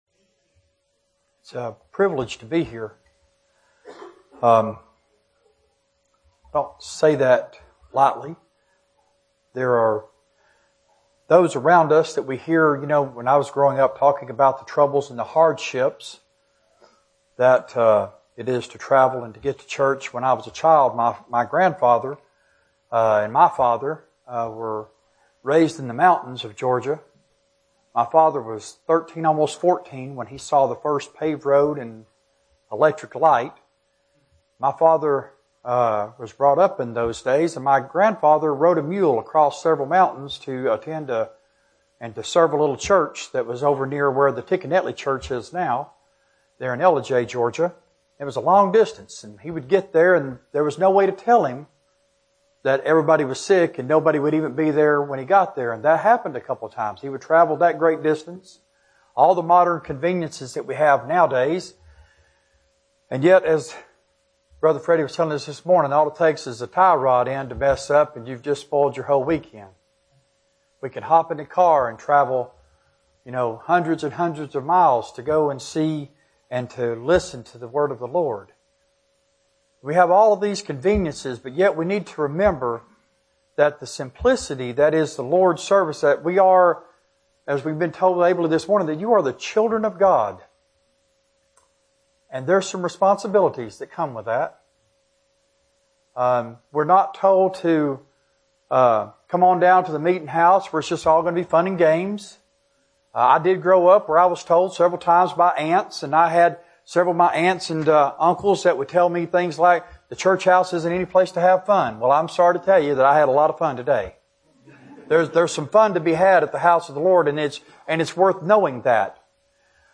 Sermons preached at Bethlehem – OKC • Page 55